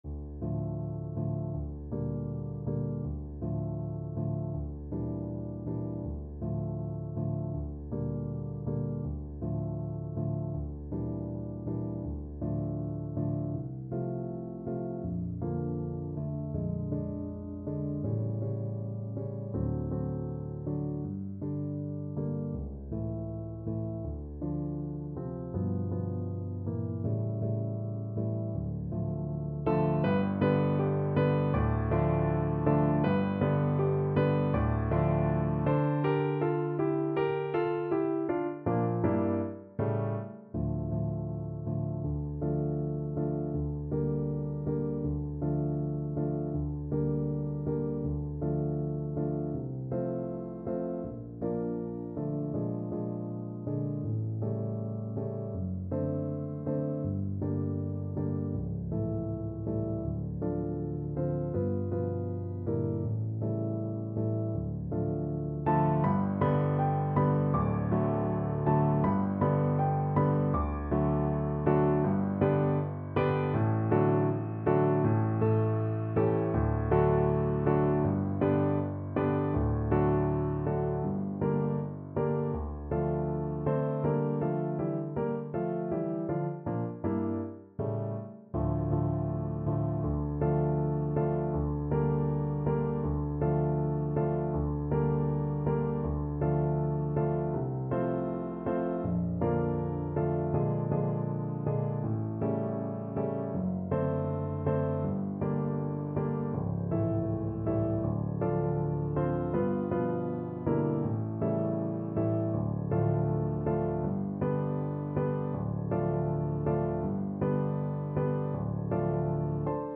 ~ = 100 Andante
Classical (View more Classical Trombone Music)